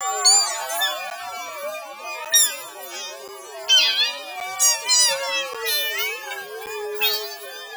After changing the speed the pitch of the audio was too high to make out a single word.
The audio was grabbed from The Doctor Who special of 2012.